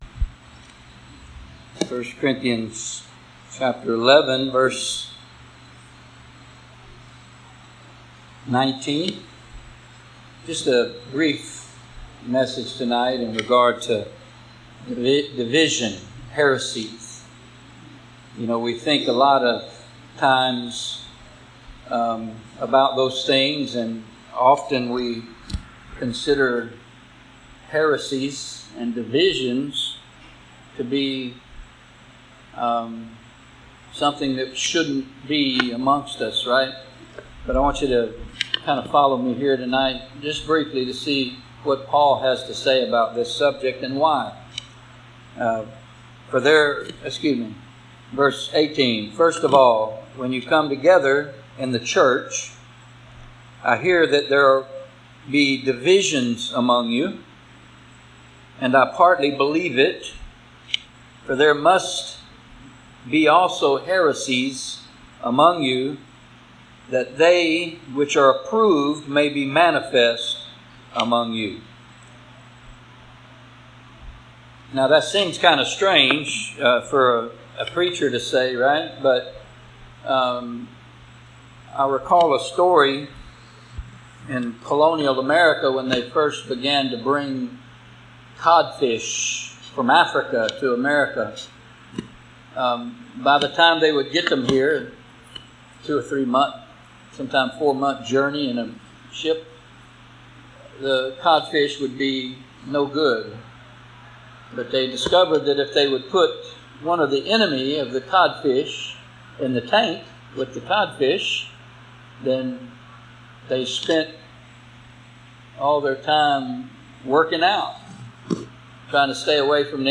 1Corinthians 11:18-19 Service Type: Thursday Evening Topics